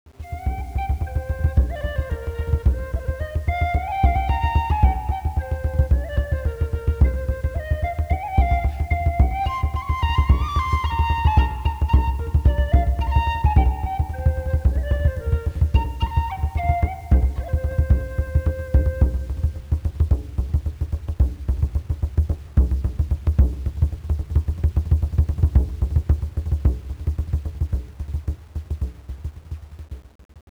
drum
bodhran
drum.wav